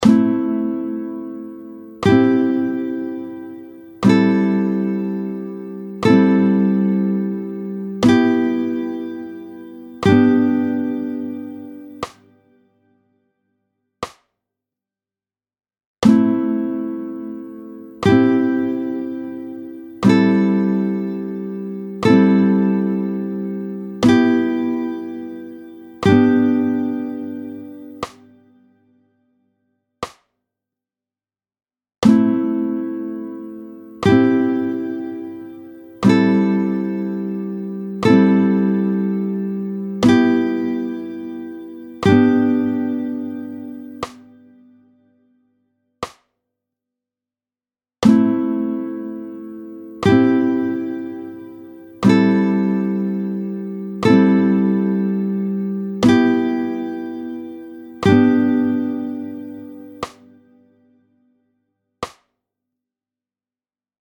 19-01 Sonorités accords majeurs et mineurs, tempo 30